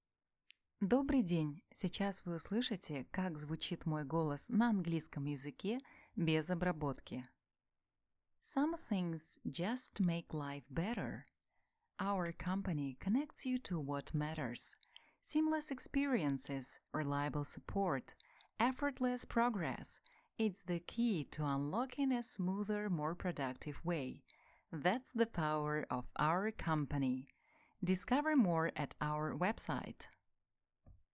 Жен, Другая/Средний
Конденсаторный микрофон Behringer B-1, звуковая карта Audient Evo 4